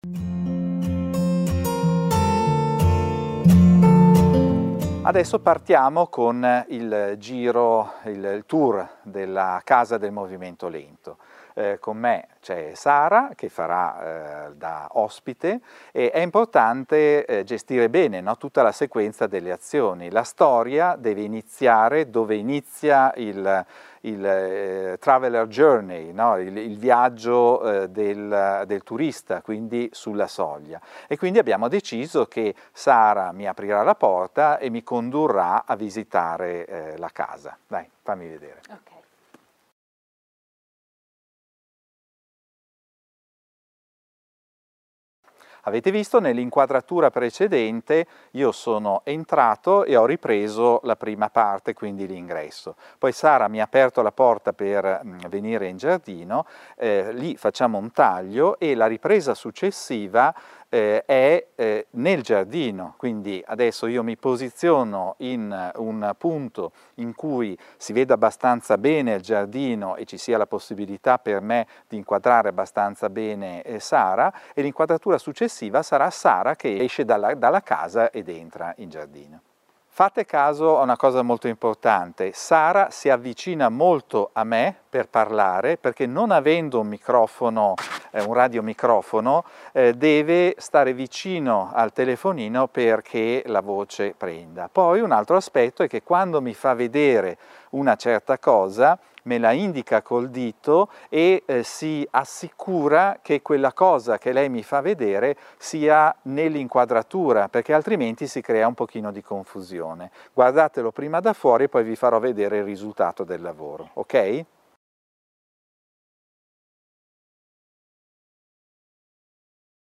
lezione